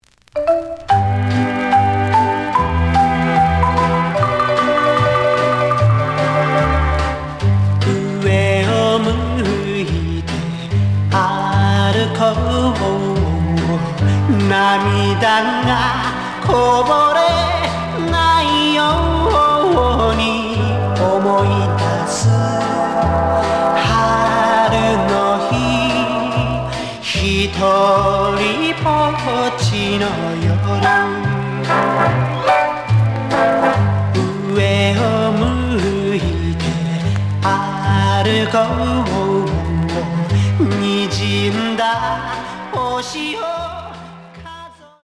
試聴は実際のレコードから録音しています。
The audio sample is recorded from the actual item.
●Format: 7 inch
●Genre: Rock / Pop